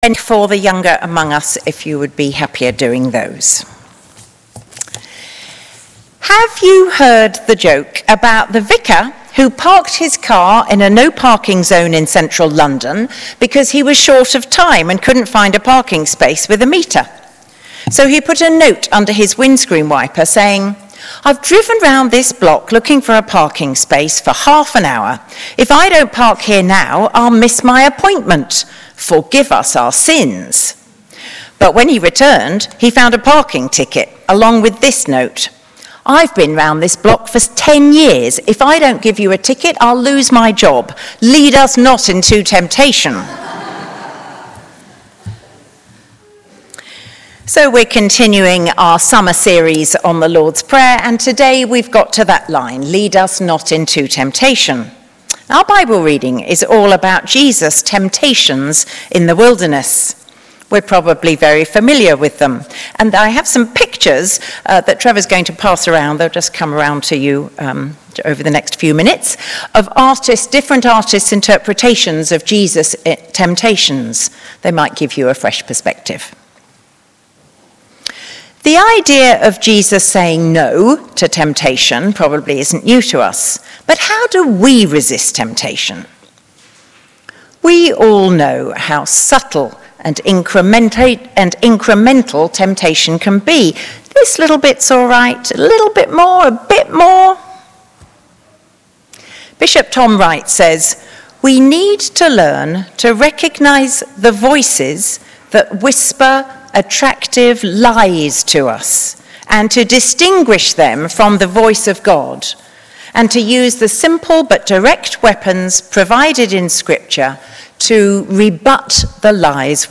Listen to our 9.30am and 11.15am sermon here: